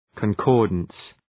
Προφορά
{kɒn’kɔ:rdəns}